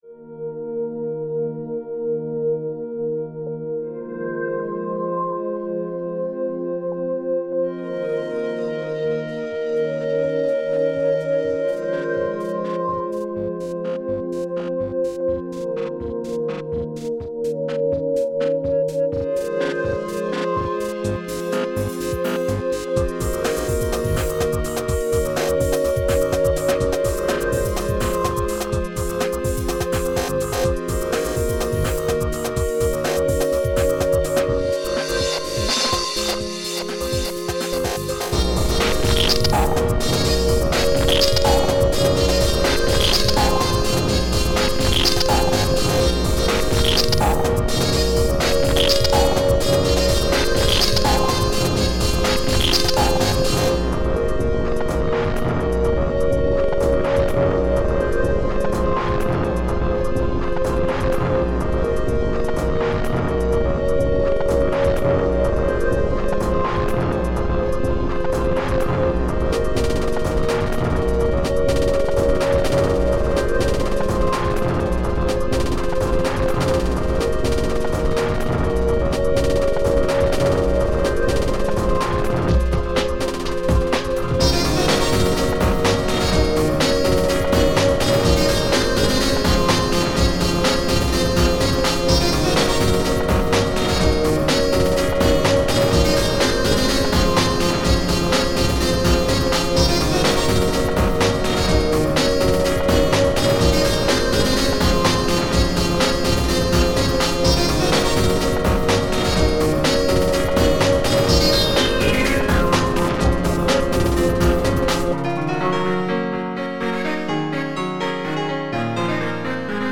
rough ambient